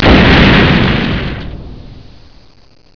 XPLODE1.WAV